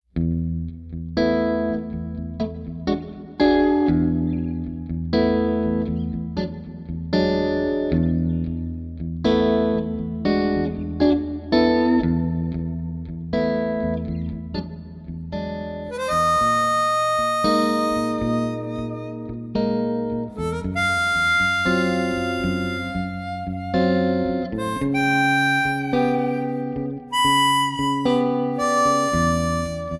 Guitar
Harmonica